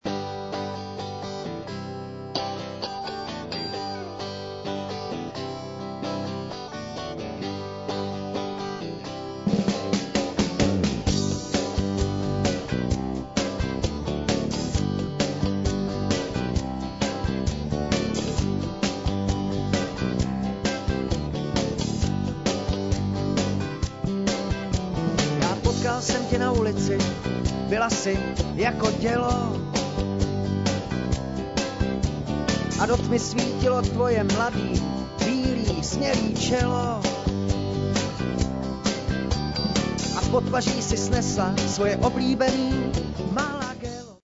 Rockový písničkář